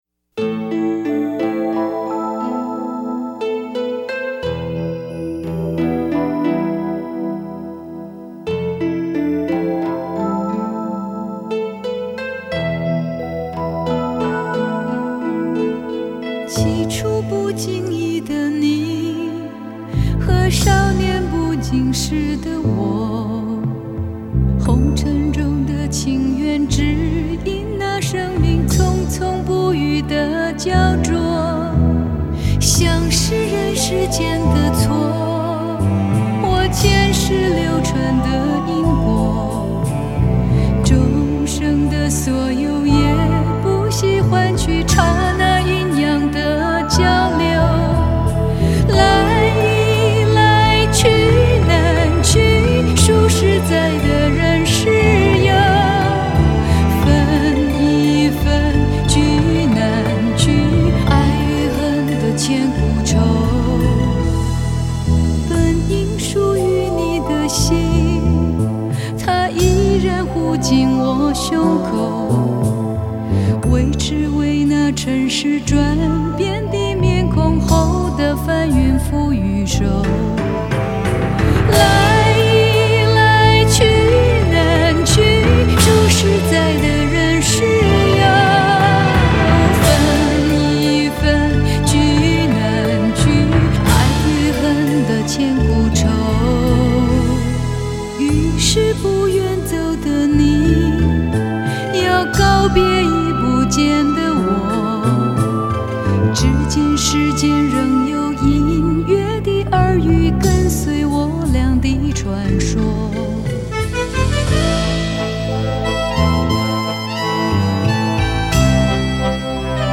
专辑类型：流行音乐